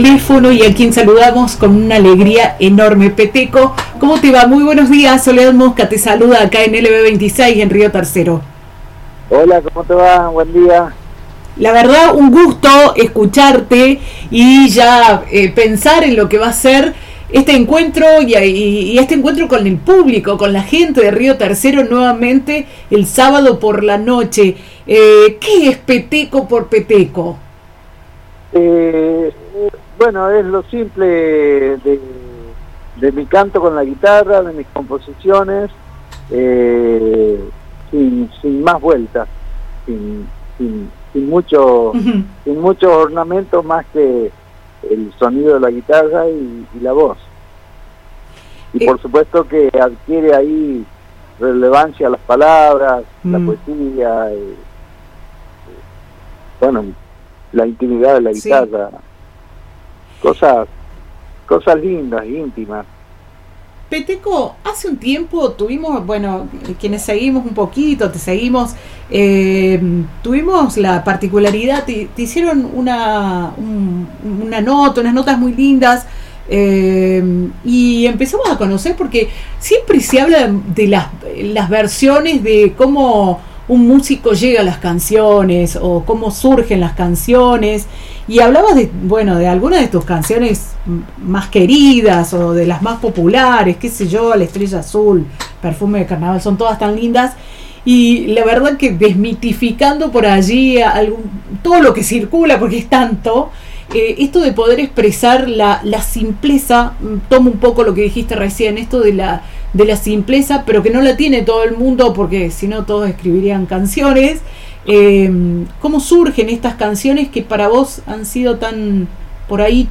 En una charla íntima, Peteco nos compartió reflexiones profundas sobre la música y su reencuentro con el público riotercerense este sábado 26 por la noche en el Anfiteatro Luis Amaya.